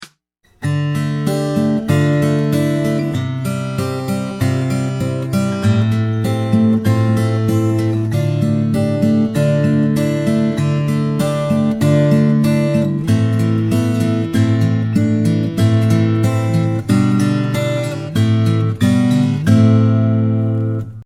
Voicing: Guitar Method